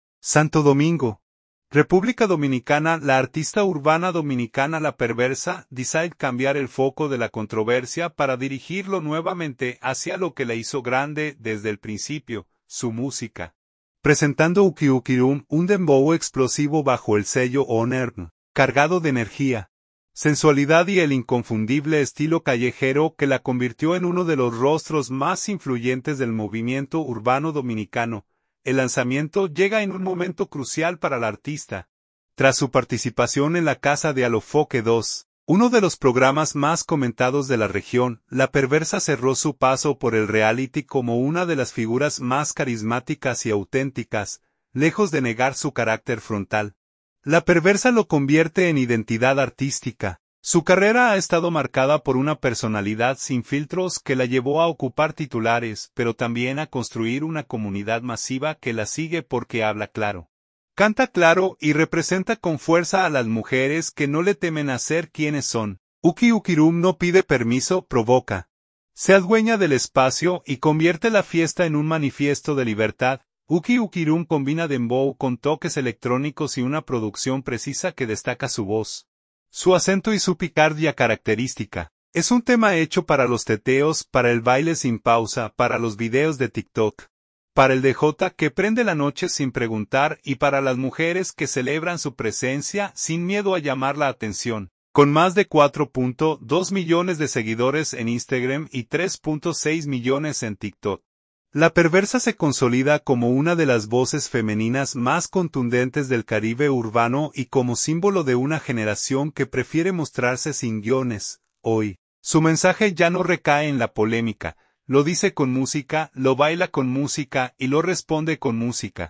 un dembow explosivo